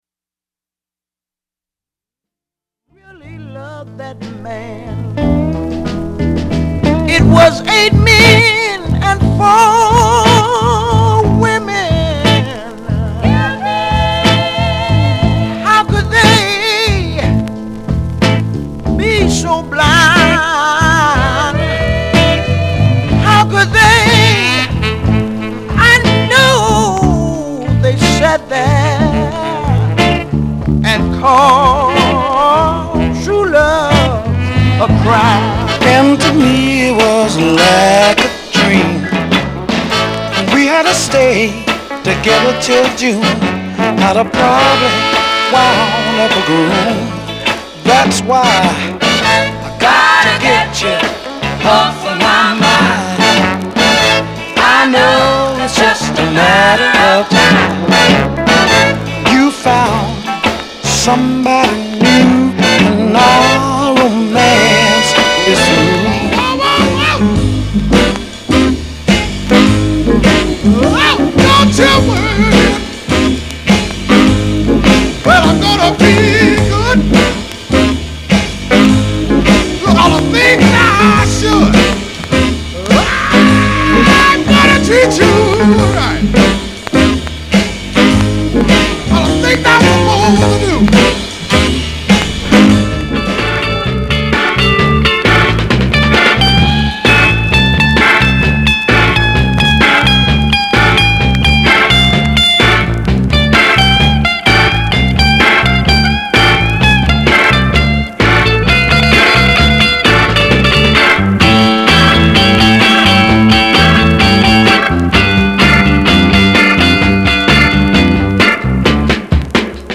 /盤質/両面やや傷あり/US PRESS